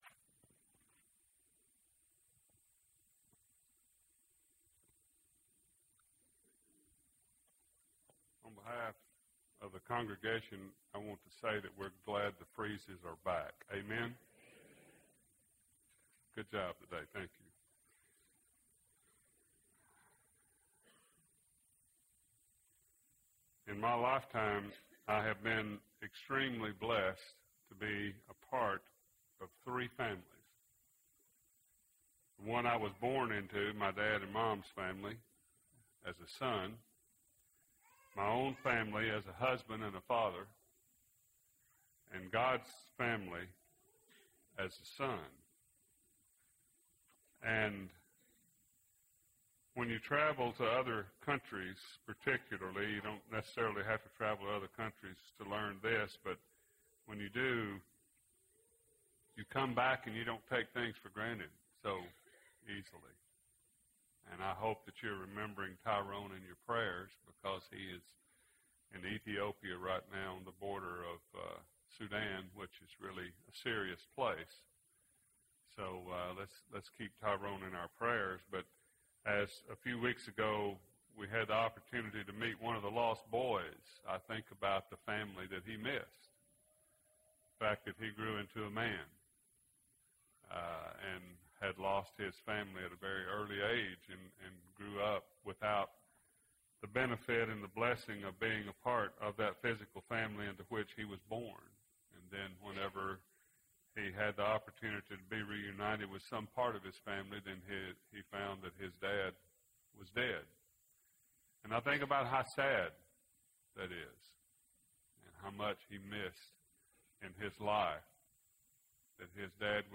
Sunday PM Sermon